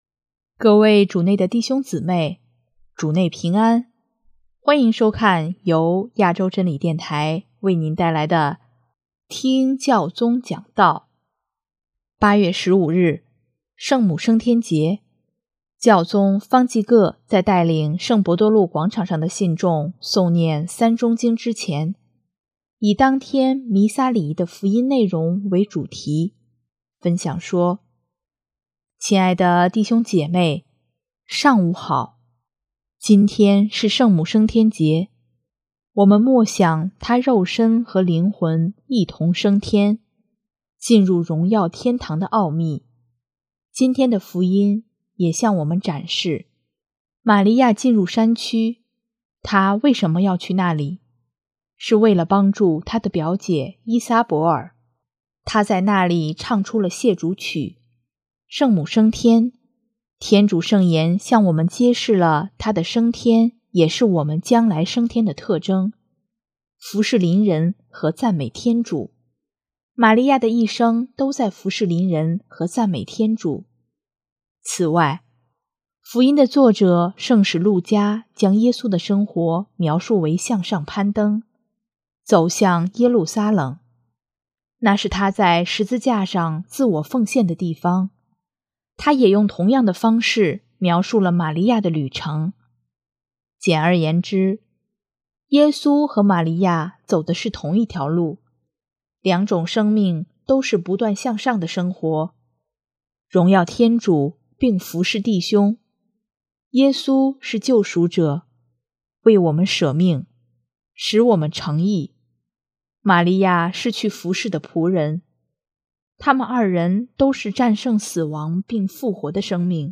8月15日，圣母升天节，教宗方济各在带领圣伯多禄广场上的信众诵念《三钟经》之前，以当天弥撒礼仪的福音内容为主题，分享说：